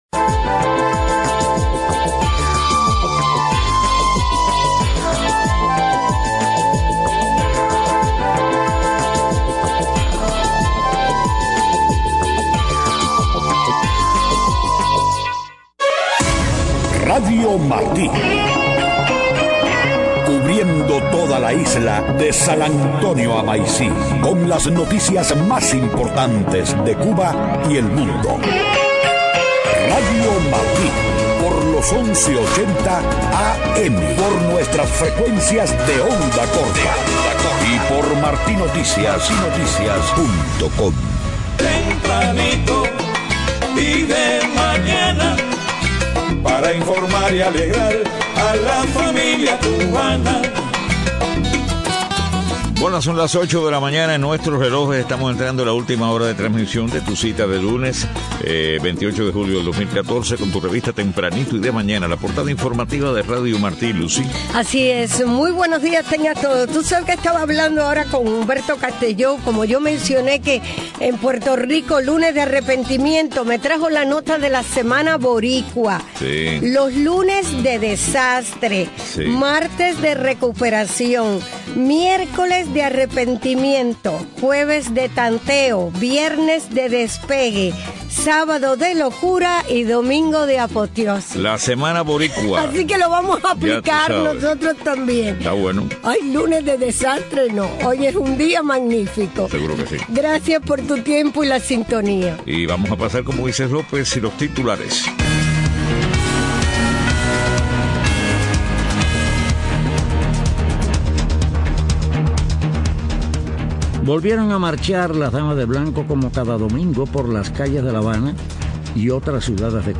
8:00 a.m. Noticias: Damas de Blanco asisten a misas y marchan por las calles de pueblos y ciudades de Cuba. Presidentes centroamericanos esperan ayuda prometida por Casa Blanca para frenar marea de niños emigrantes. Departamento de Estado divulga informe anual sobre libertad religiosa en el mundo.